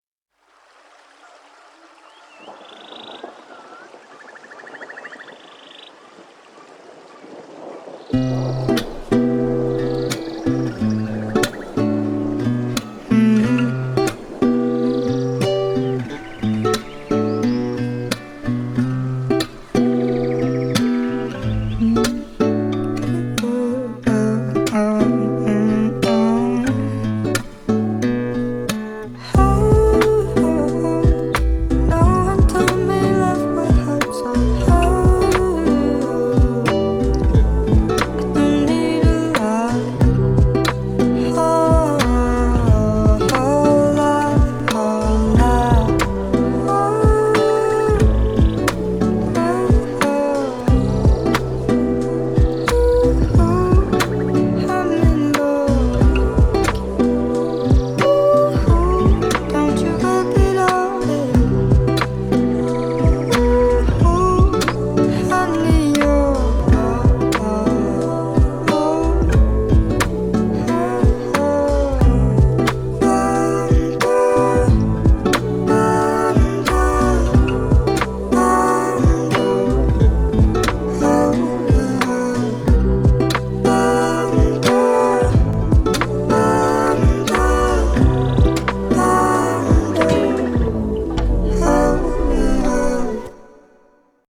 Whether you’re a fan of heartfelt ballads or soulful R&B